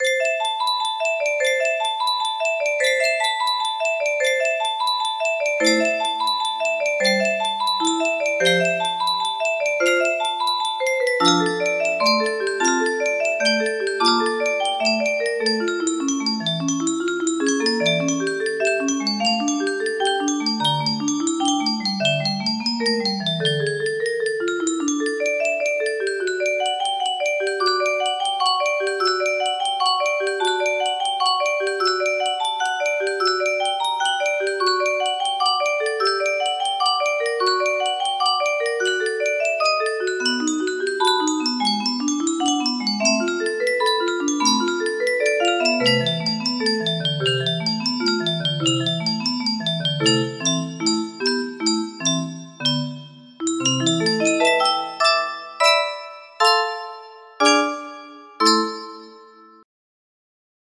music box melody
Full range 60